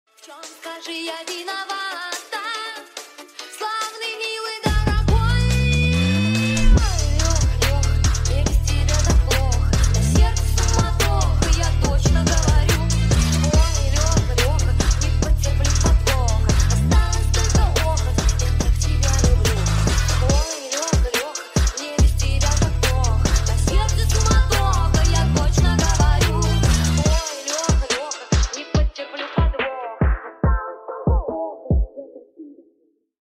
Громкие Рингтоны С Басами
Фонк Рингтоны
Рингтоны Ремиксы » # Поп Рингтоны